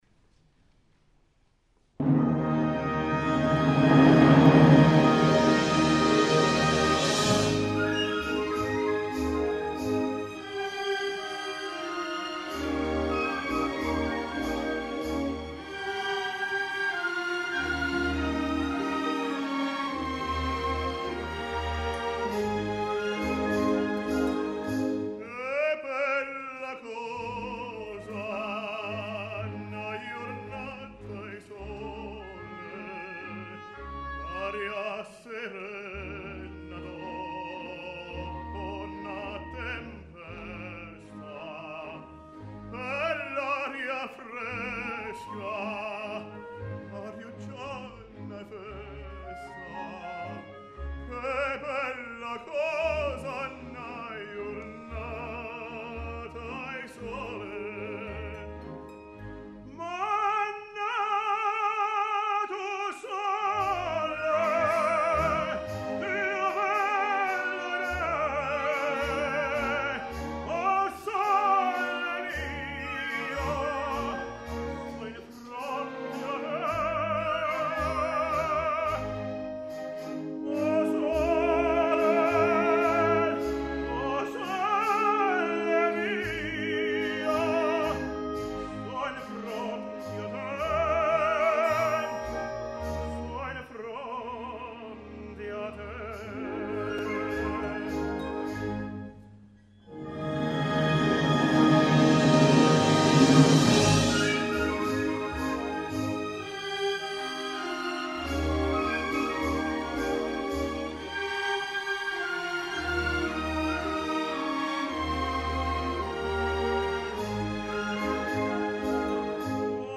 Tenor Soloist